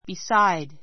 beside A1 bisáid ビ サ イ ド 前置詞 ～のそばに , ～のそばの beside the river beside the river 川のそばに Sit beside me.